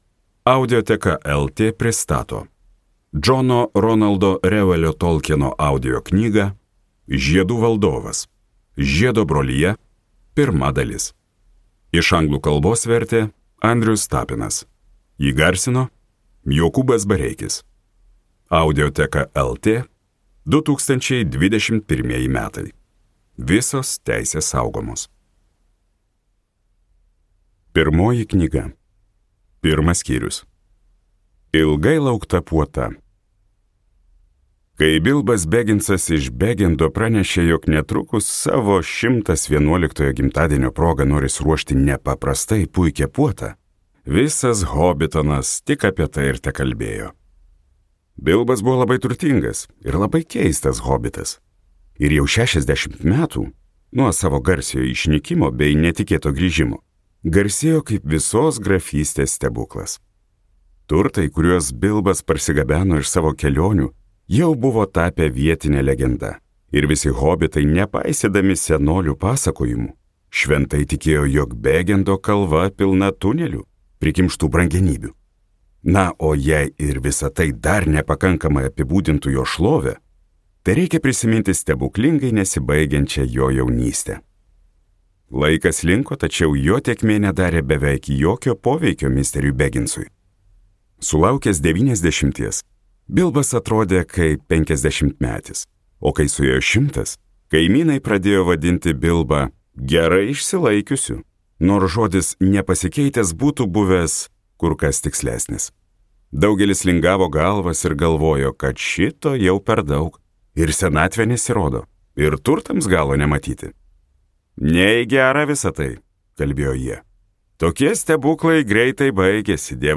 Skaityti ištrauką play 00:00 Share on Facebook Share on Twitter Share on Pinterest Audio Žiedų valdovas.